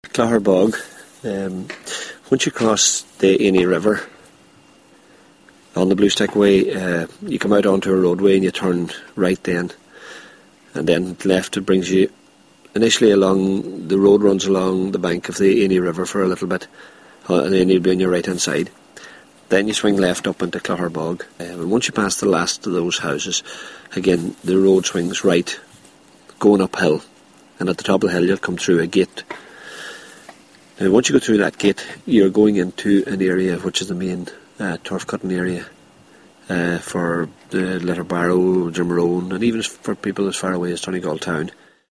Our audio piece comes from local guide